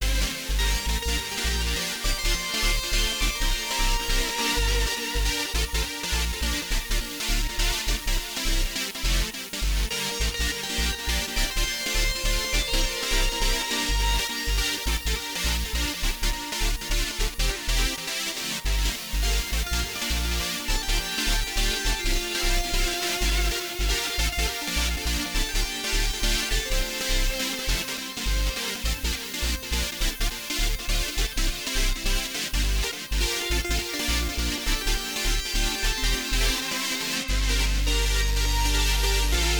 Dolby Atmos Binaural Test File Downloads
* headphones/earbuds only